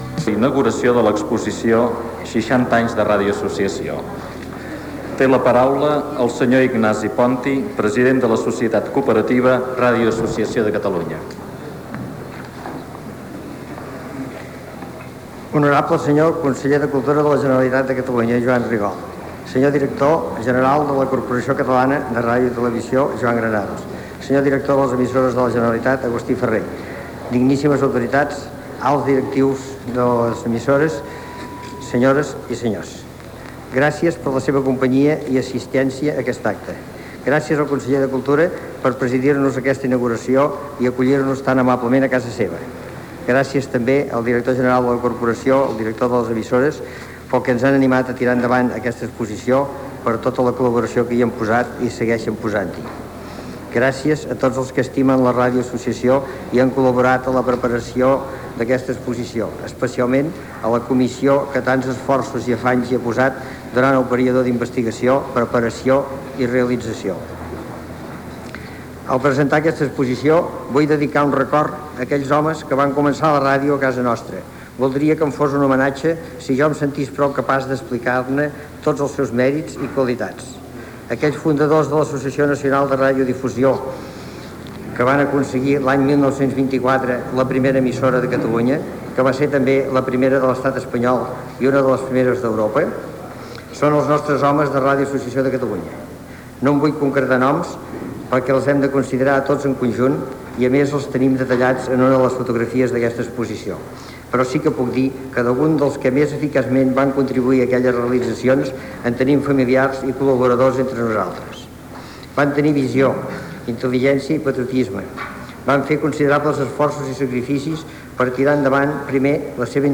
Transmissió de la inauguració de l'exposició "60 anys de Ràdio Associació de Catalunya", des del Palau Marc de Barcelona:
Informatiu